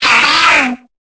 Cri de Lombre dans Pokémon Épée et Bouclier.